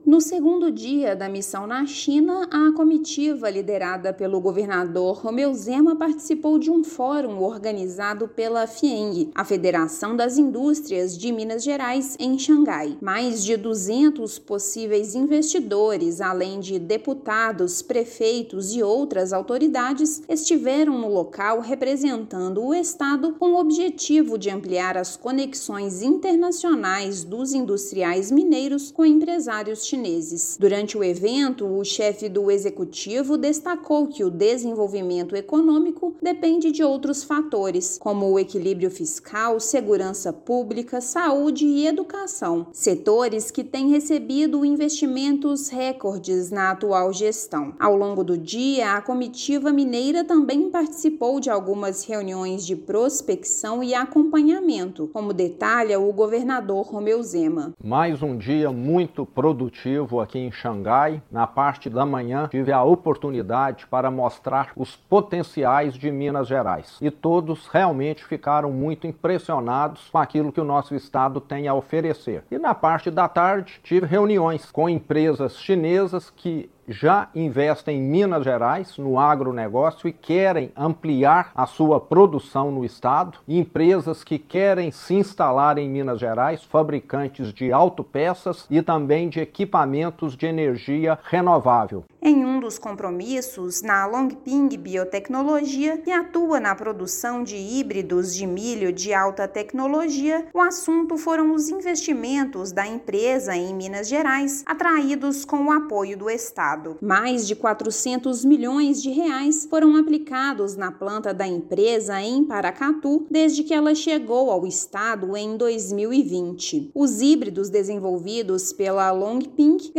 [RÁDIO] Governo de Minas apresenta potencialidades do estado a empresários chineses
Em Xangai, governador e membros da comitiva participaram do China Business Forum e de reuniões bilaterais de prospecção. Ouça matéria de rádio.